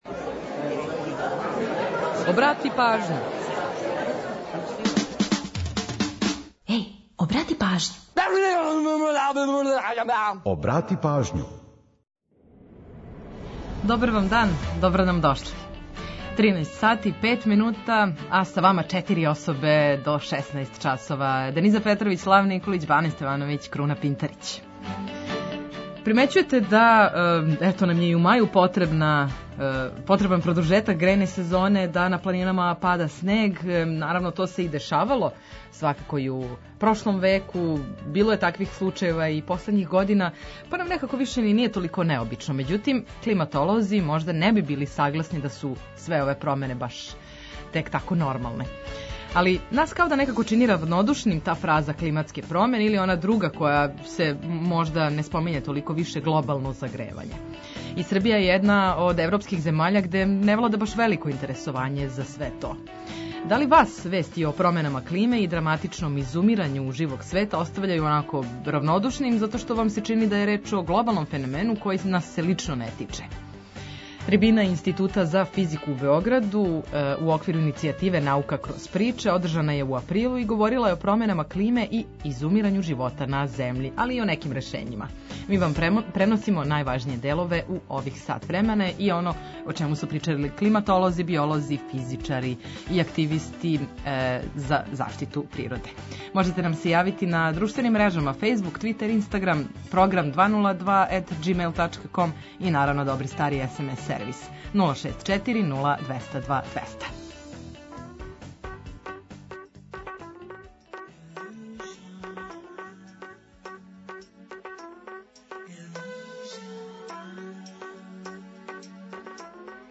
Ми вам преносимо најважније делове.